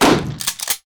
bl_shotgun_shoot.ogg